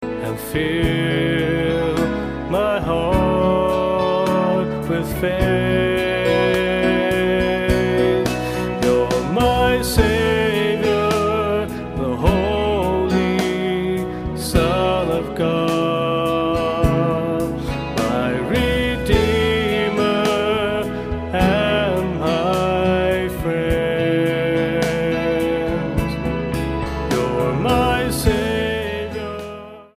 STYLE: Pop
just drums, keyboards and guitar
rich baritone voice